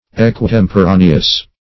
Search Result for " equitemporaneous" : The Collaborative International Dictionary of English v.0.48: Equitemporaneous \E`qui*tem`po*ra"ne*ous\, a. [L. aequus equal + tempus, temporis, time.]